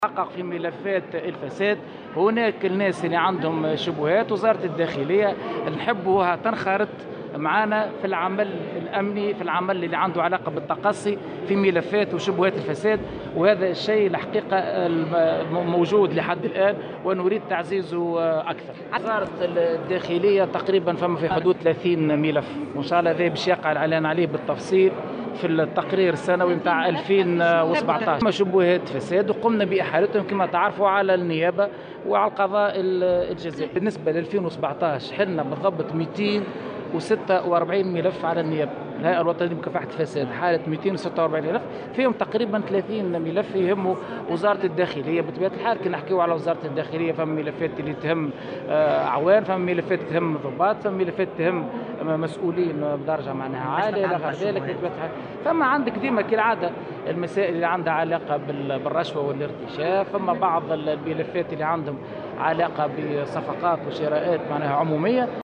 وأضاف في تصريح اليوم لمراسلة "الجوهرة أف أم" على هامش يوم دراسي حول دور قوات الأمن الداخلي في مكافحة الفساد، أن الملفات تتعلق بقضايا رشوة وصفقات و شراءات عمومية، مشيرا إلى أن وزارة الداخلية قد تعاونت بشكل كبير مع الهيئة.